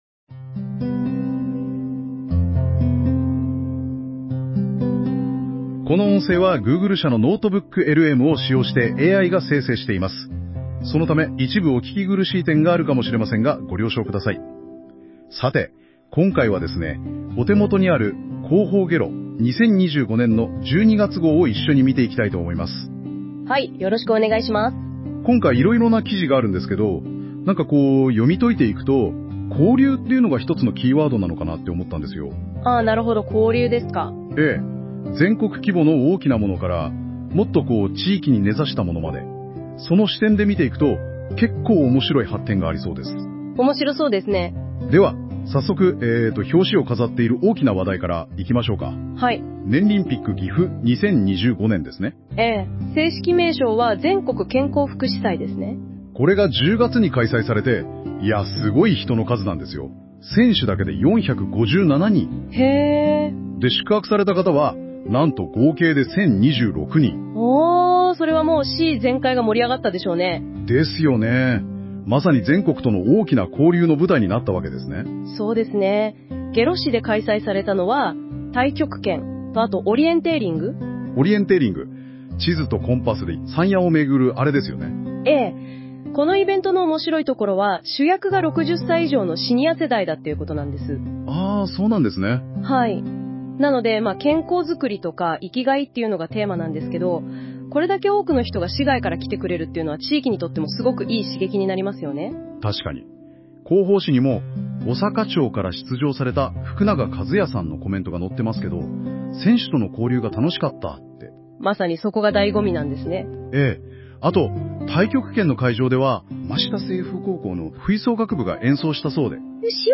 広報げろを解説した音声データを公開しています！
下呂市では、令和7年5月から市民の皆さんの利便性向上のため、生成AIを活用した行政情報の音声提供サービスを試験的に開始します。